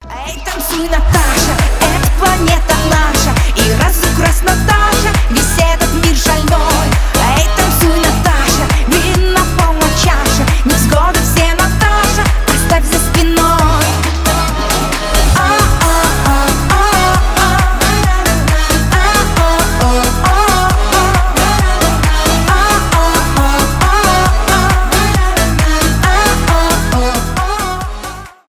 поп
танцевальные , веселые